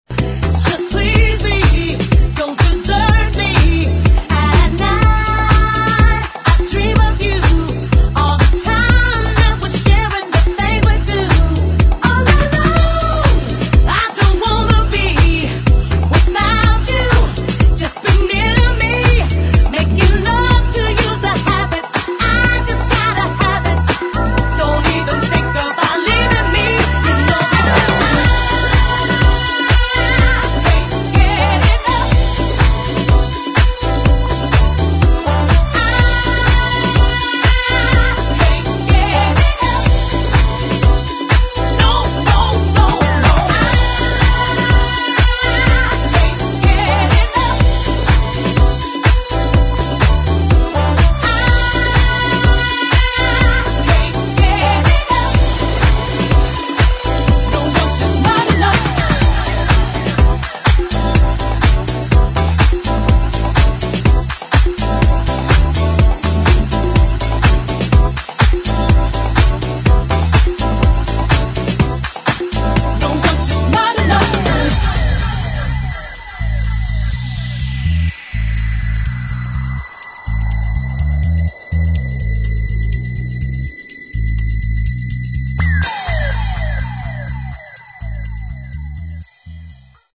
[ DISCO / HOUSE ]